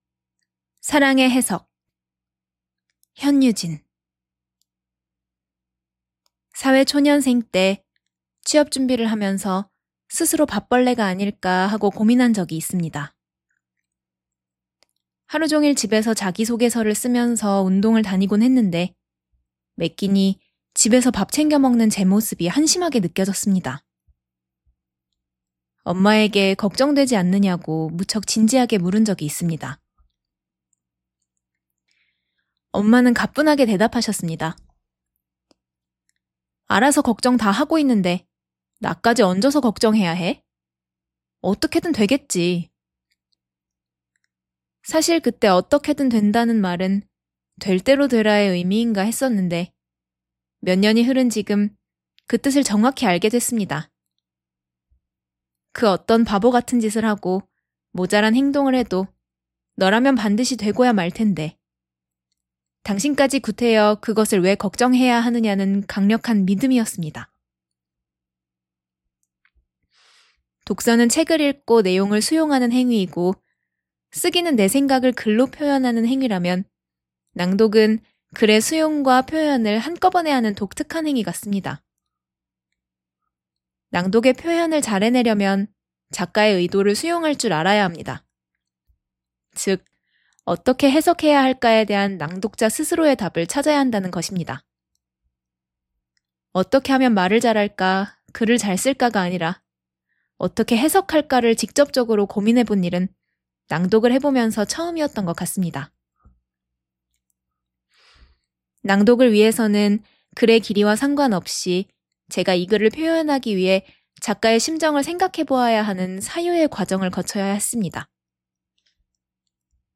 서혜정낭독연구소 2025 송년 낭독회 '나의 낭독이야기'
아래, 제가 낭독했던 저의 글 전문과 제 목소리로 낭독한 파일을 올려둡니다.